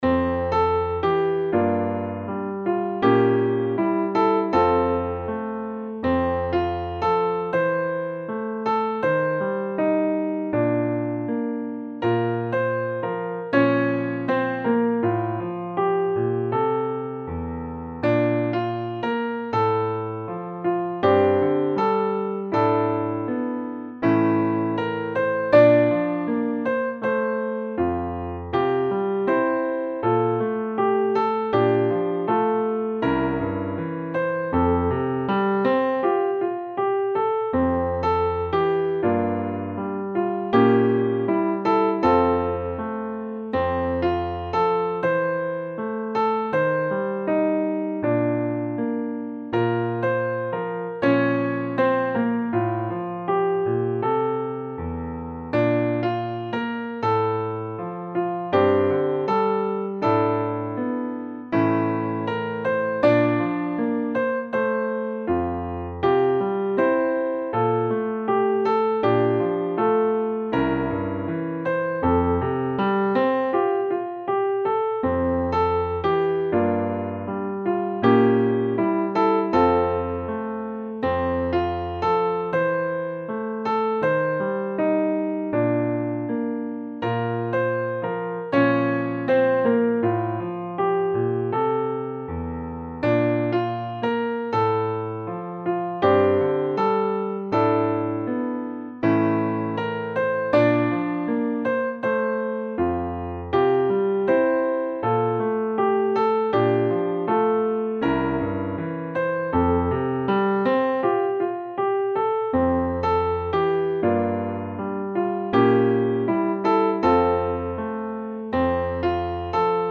The hymn-like melody is quite melancholic and reflective, so  it’s not a simple song of praise.